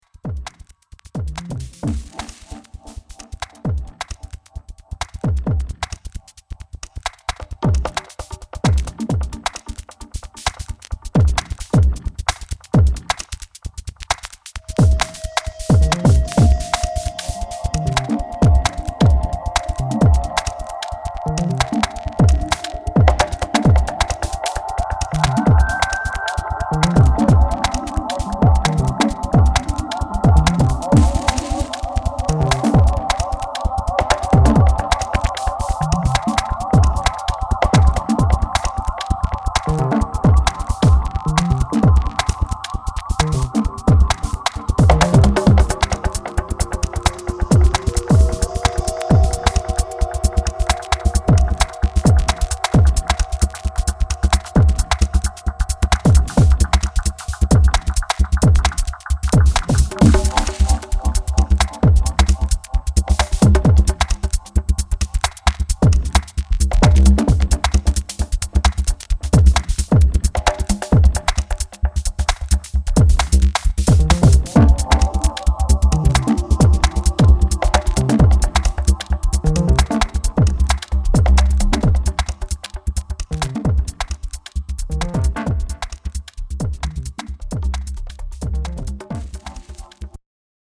フラッシュバックするダブ処理のブロークンビーツ